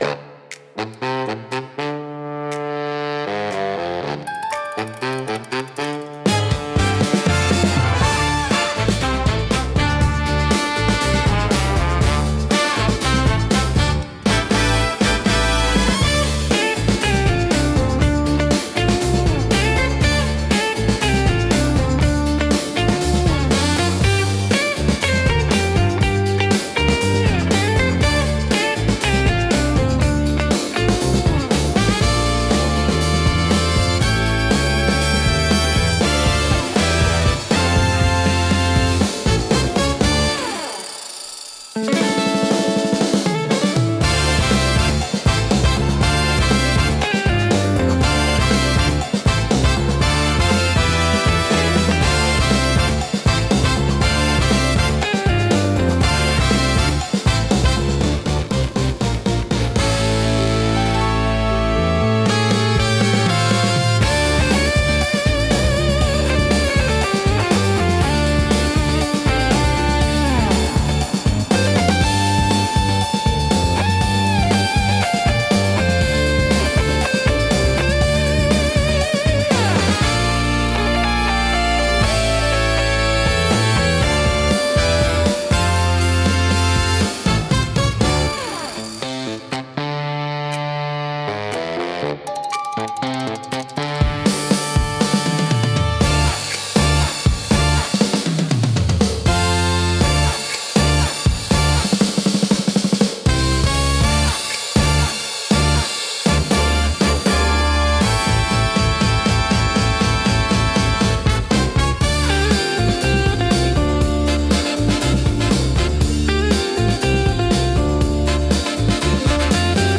不穏さとエネルギーが同居し、聴く人の集中力を高めつつドキドキ感を作り出します。